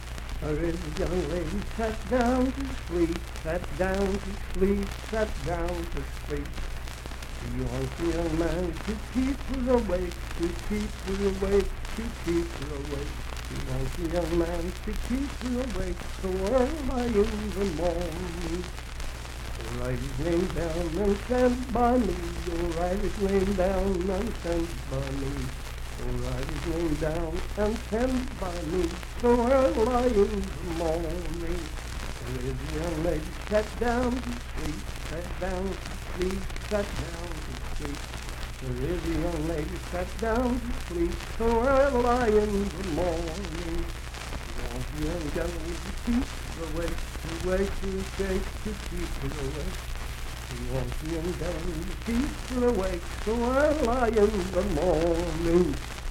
Unaccompanied vocal music
Verse-refrain 4(4).
Voice (sung)
Grant County (W. Va.)